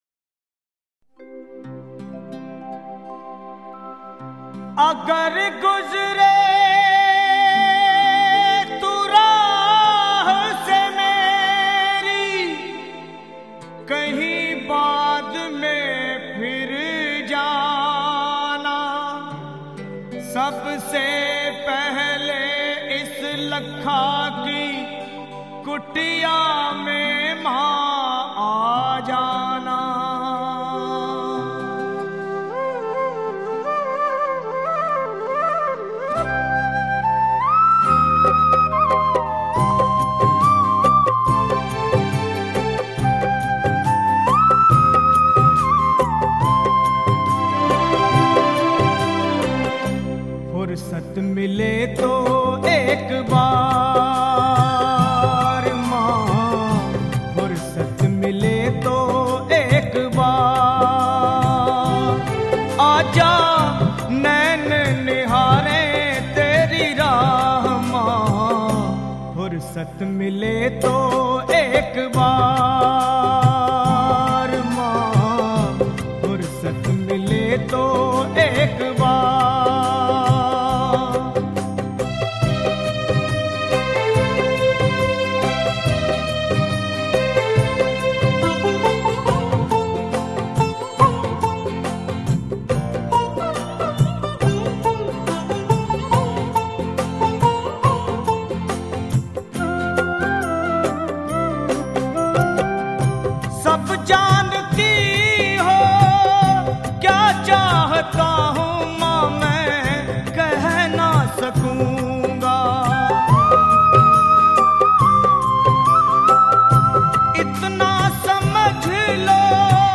Bhakti Sangeet
Best bhajan for navratri special.